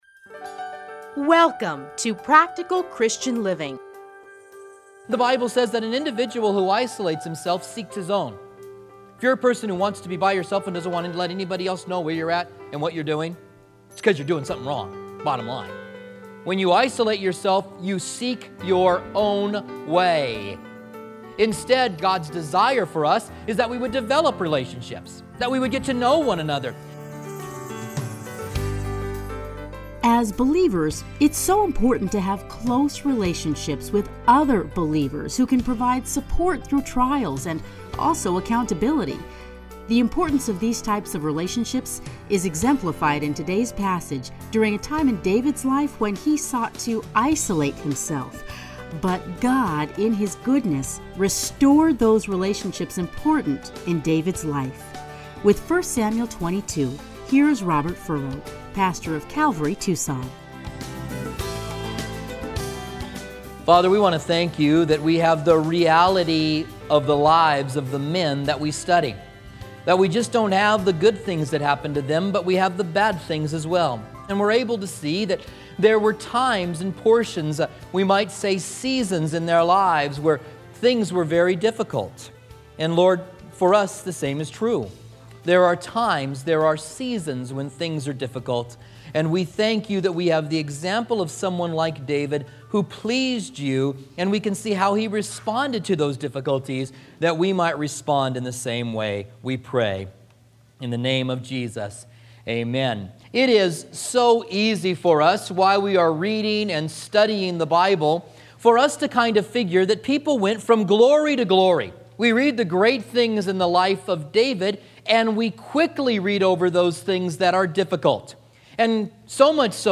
30-minute radio programs